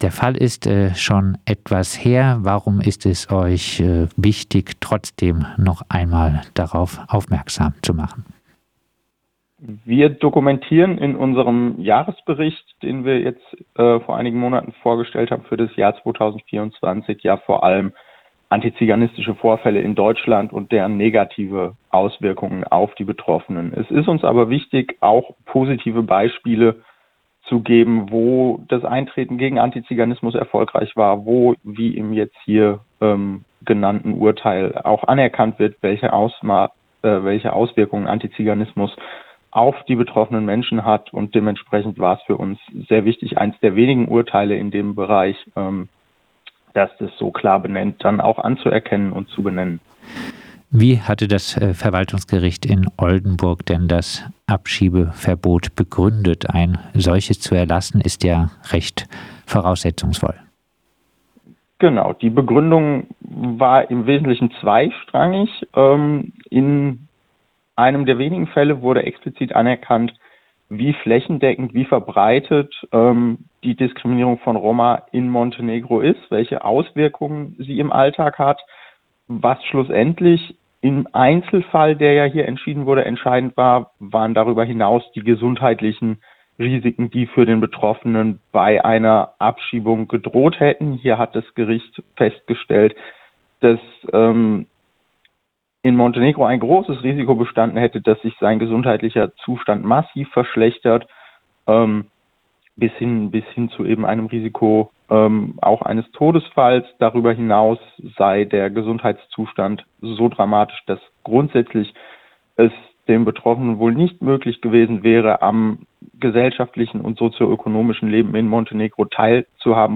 Gericht untersagt Abschiebung von Rom nach Montenegro - MIA im Gespräch mit Radio Dreyeckland - Melde- und Informationsstelle Antiziganismus